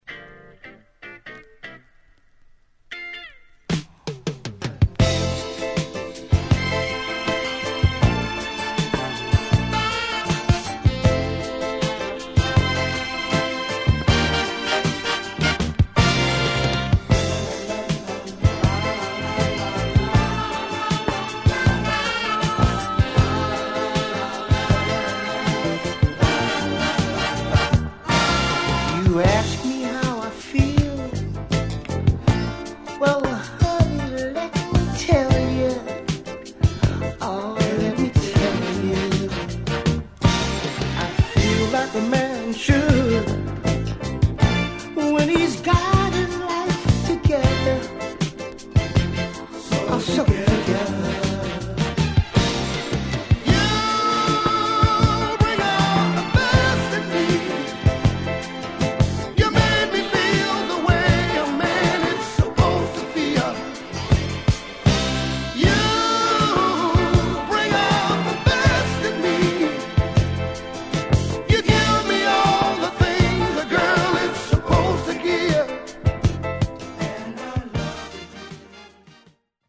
Mid~Mellow Soul